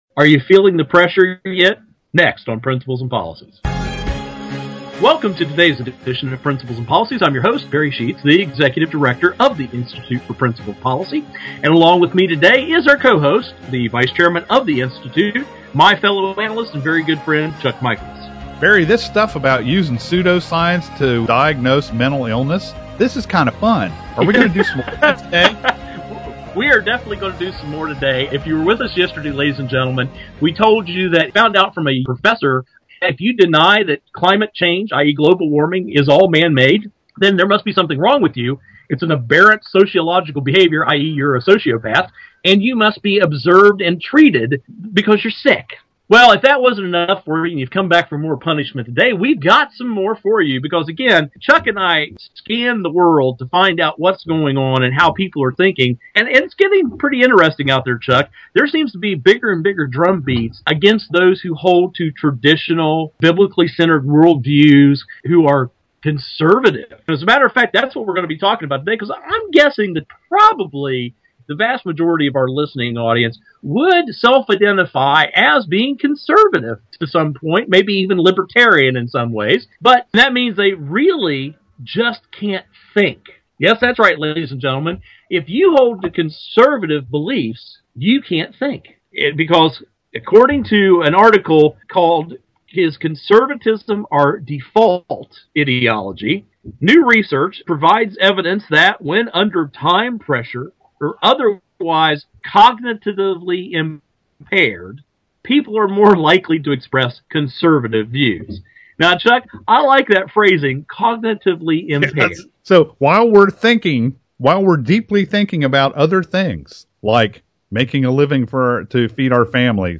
Our Principles and Policies radio show for Tuesday April 3, 2012.